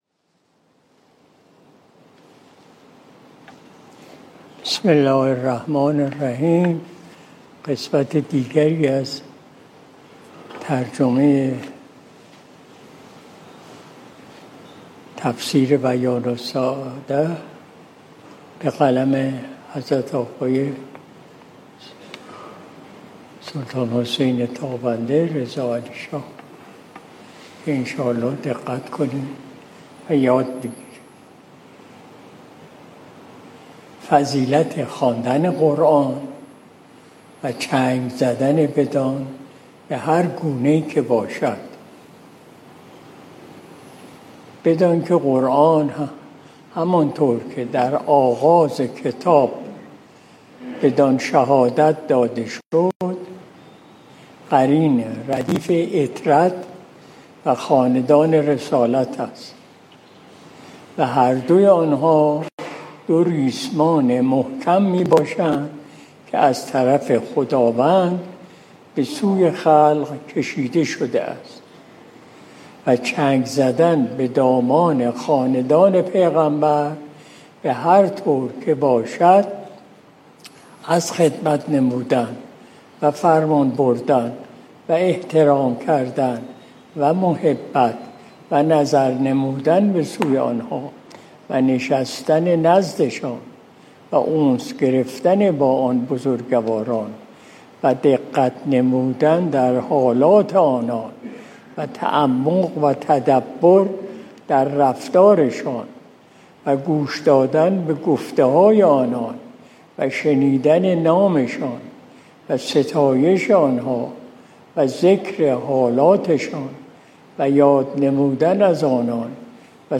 مجلس شب جمعه ۱۲ مرداد ماه ۱۴۰۲ شمسی